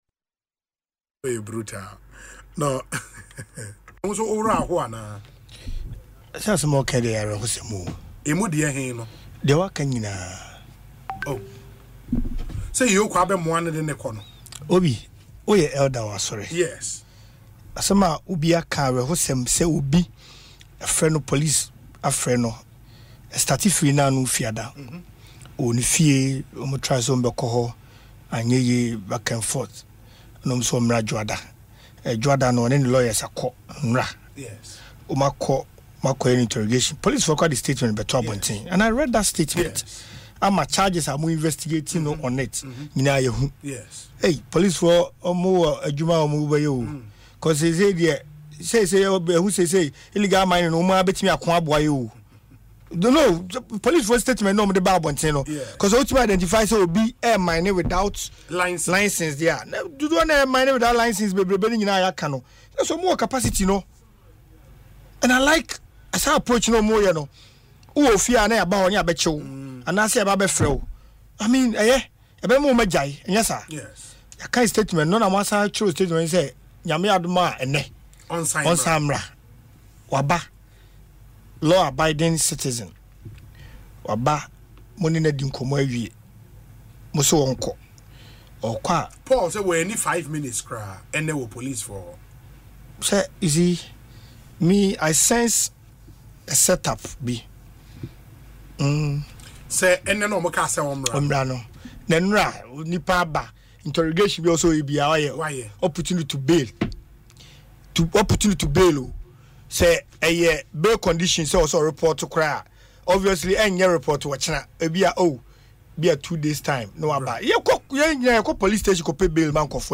Speaking on Asempa FM’s Ekosii Sen, Mr Gyamfi, the former District Chief Executive (DCE) for Amansie South, expressed deep concern about the arrest.